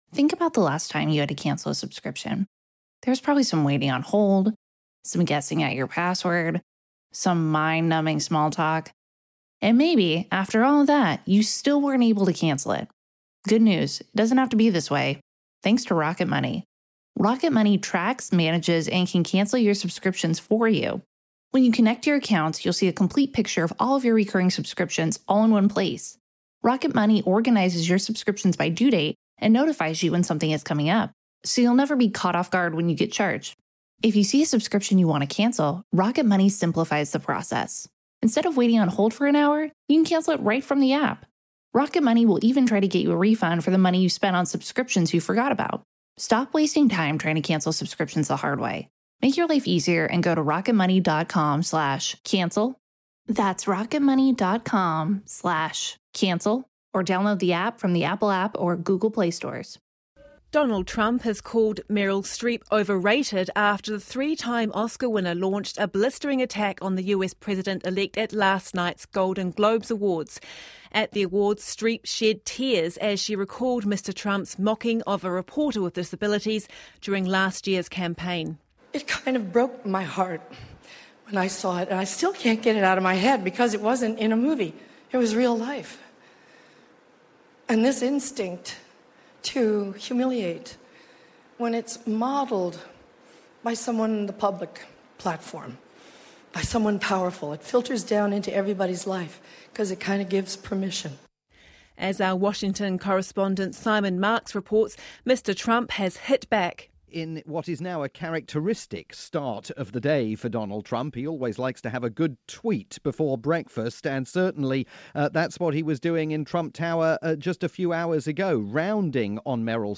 live appearance discussing the battle between Donald Trump and Meryl Streep.